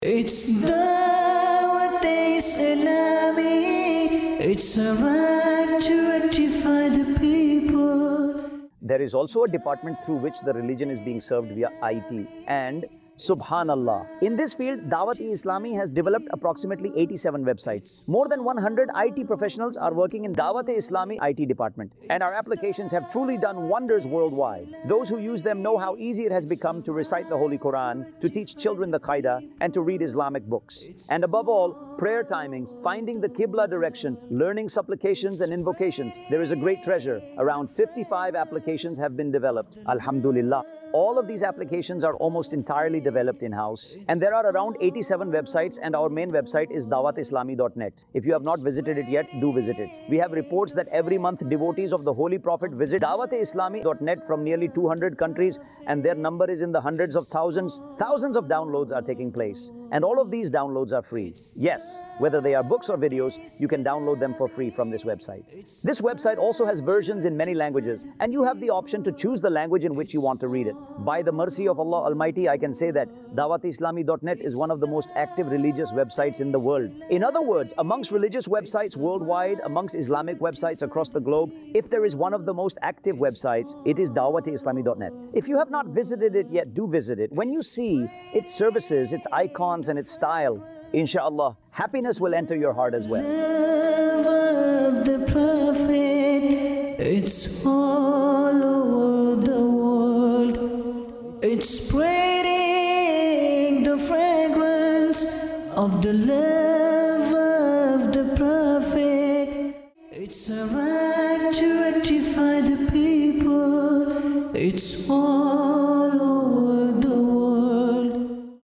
khutba
I.T Department | Department of Dawateislami | Documentary 2026 | AI Generated Audio
آئی ٹی ڈیپارٹمنٹ | شعبہِ دعوت اسلامی | ڈاکیومینٹری 2026 | اے آئی جنریٹڈ آڈیو